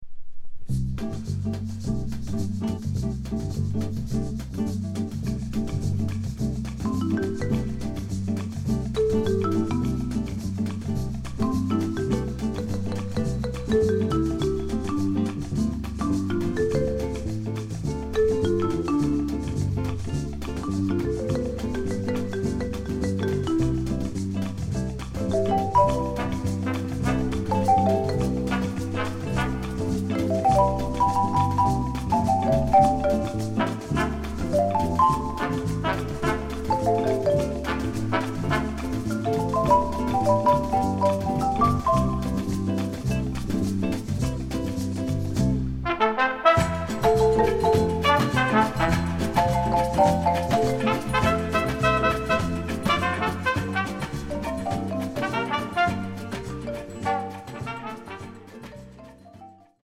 ジャズ、ラテン、サンバ、ボサノバ、マリアッチ(メキシコの伝統音楽)のクロスオーバー音楽です。